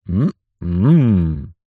Мужчина говорит ммм ммм будто вкусно 1